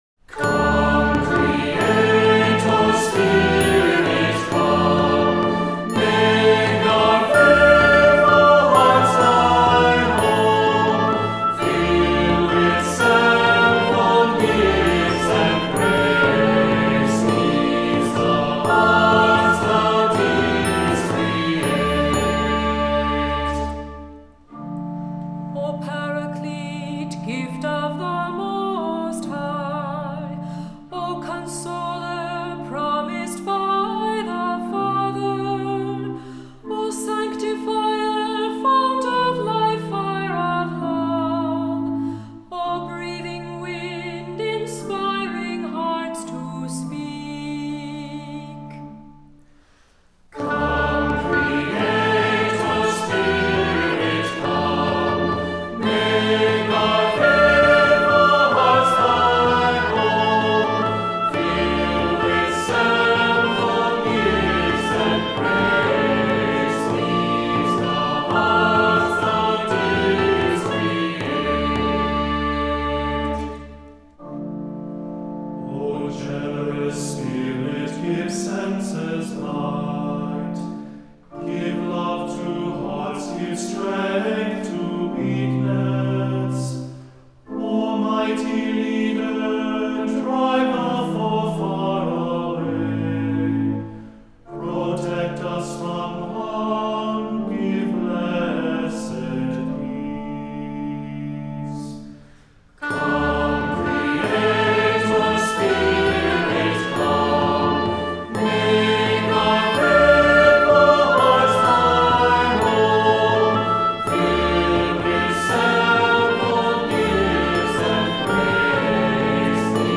Voicing: Unison; Cantor; Assembly